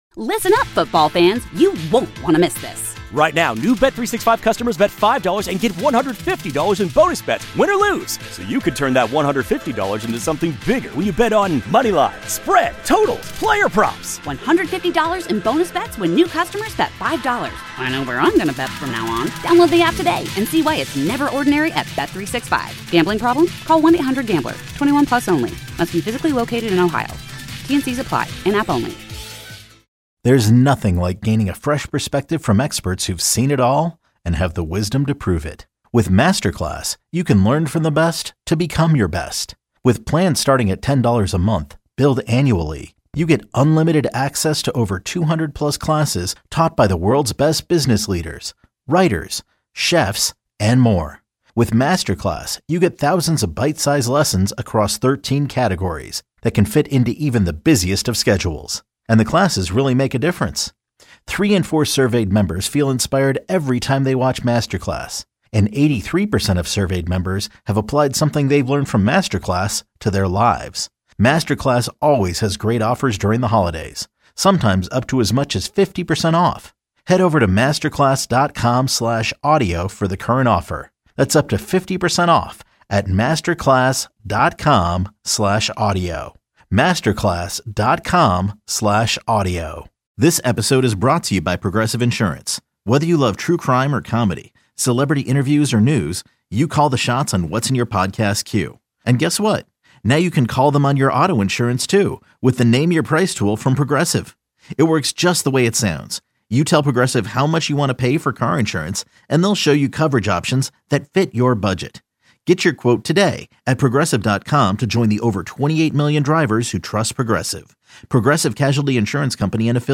fully produced news and entertainment program aired live each weekday morning from 6a-9a on KNSS.